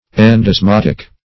Search Result for " endosmotic" : The Collaborative International Dictionary of English v.0.48: Endosmotic \En`dos*mot"ic\, a. Pertaining to endosmose; of the nature endosmose; osmotic.